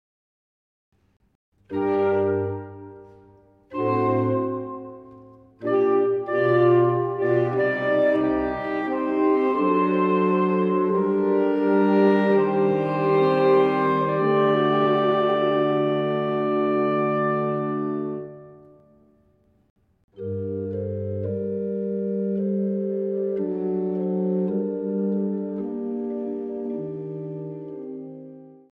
Vivace 0:20